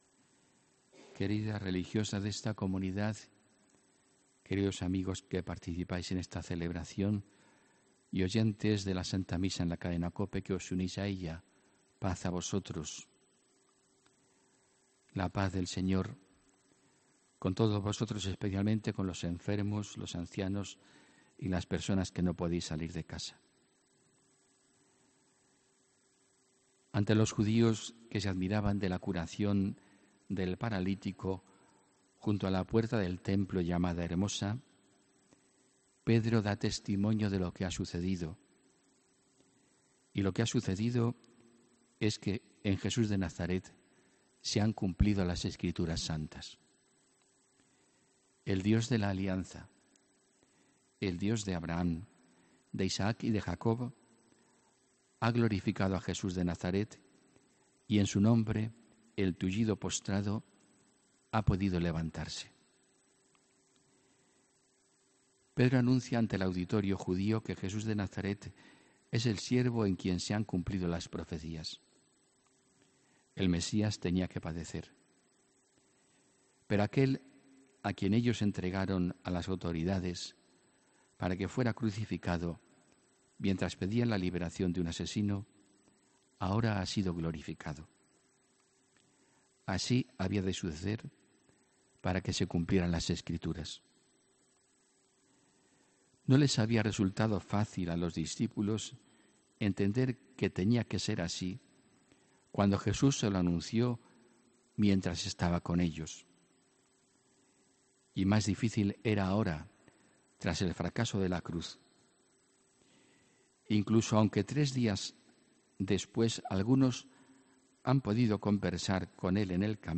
HOMILÍA 15 ABRIL 2018